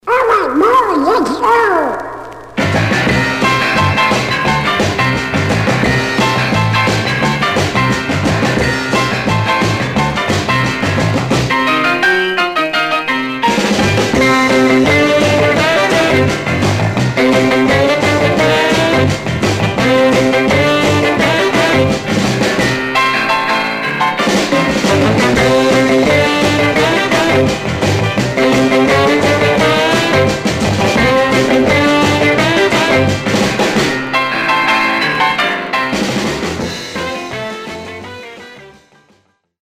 Some surface noise/wear Stereo/mono Mono
R & R Instrumental